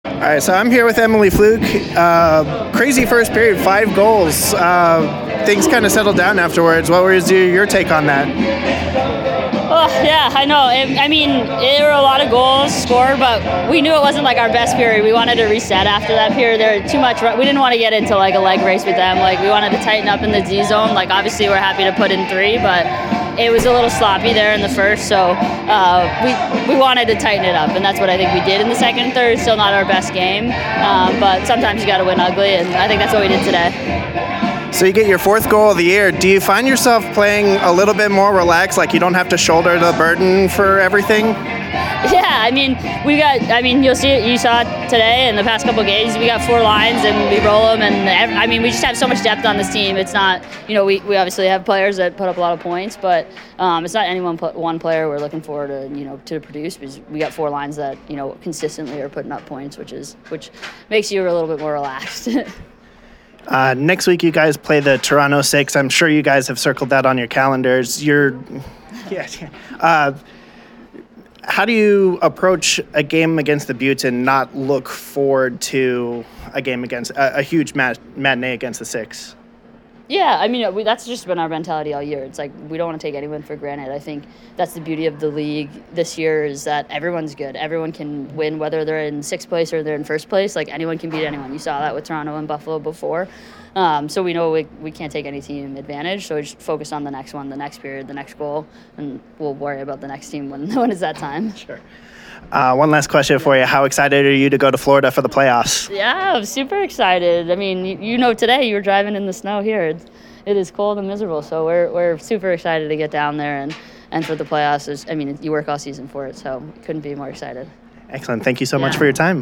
Post Game Interview: